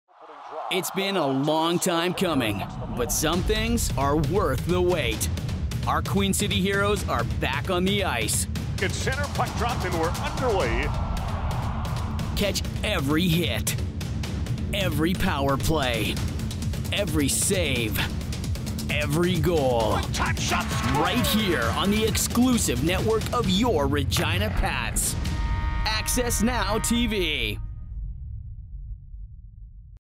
Englisch (Kanadisch)
-Professionelles Studio mit Tonkabine